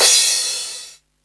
• Huge Crash Cymbal One Shot C Key 03.wav
Royality free crash cymbal audio clip tuned to the C note.
huge-crash-cymbal-one-shot-c-key-03-AvU.wav